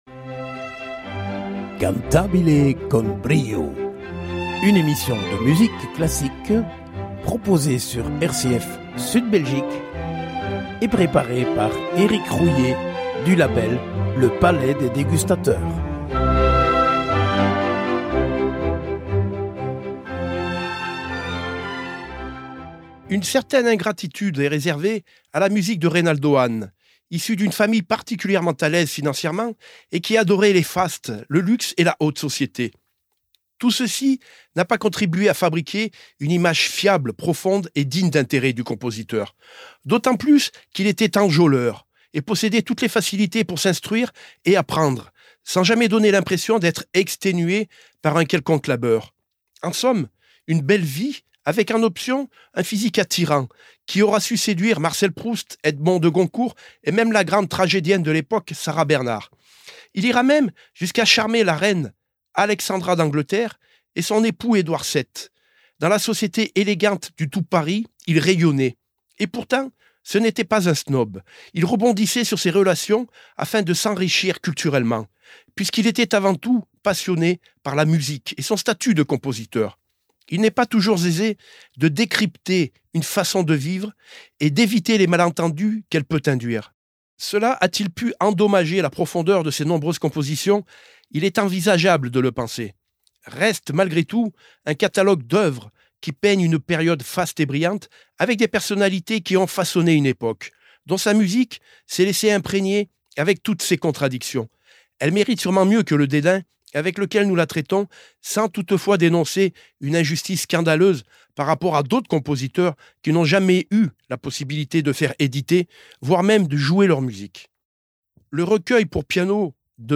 RCF Sud Belgique : Reynaldo Hahn Concerto pour piano